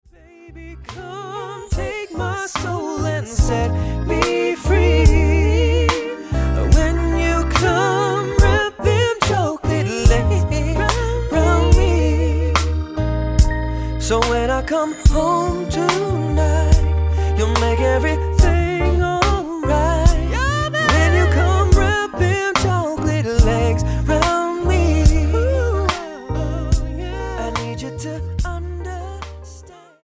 NOTE: Background Tracks 10 Thru 18